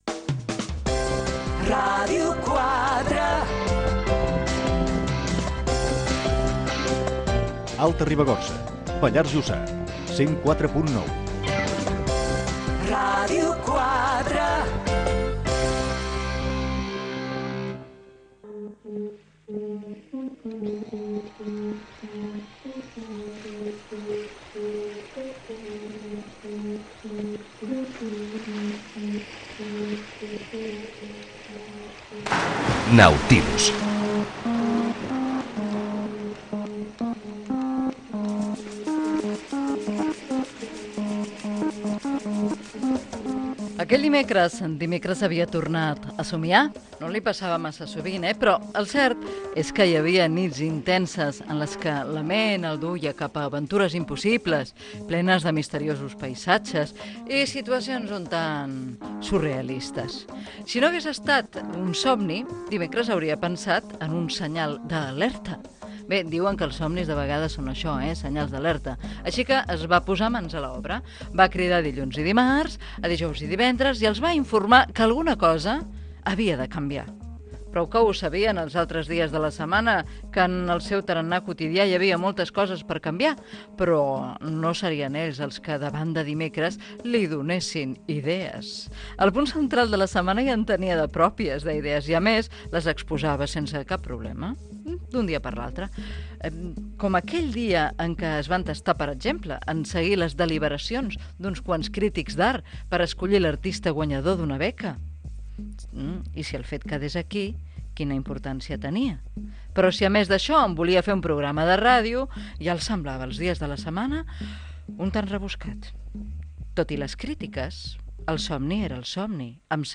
Indicatiu de la ràdio amb algunes de les freqüències, careta del programa, lectura d'una narració